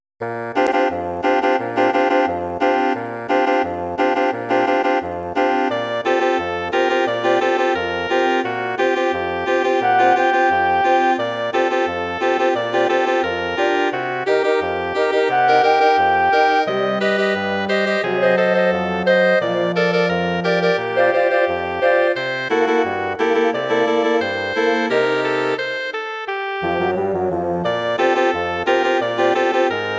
Bearbeitung für Bläserquintett
Besetzung: Flöte, Oboe, Klarinette, Horn, Fagott
Arrangement for woodwind quintet
Instrumentation: flute, oboe, clarinet, horn, bassoon